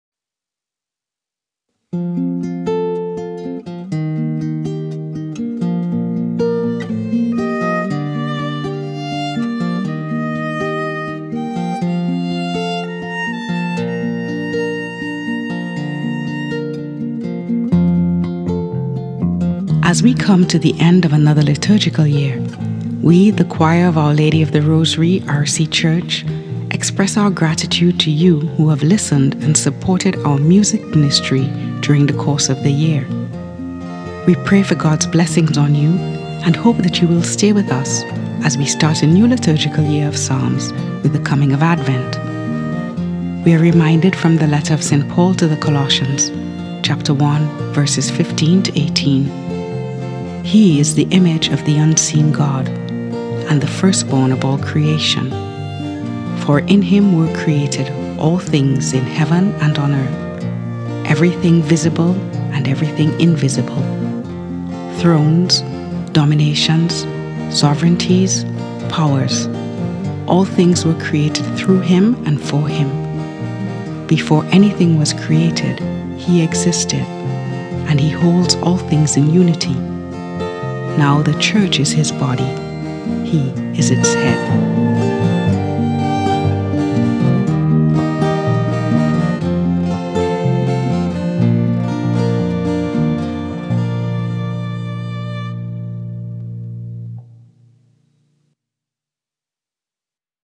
Well spoken against a beautiful backdrop of music.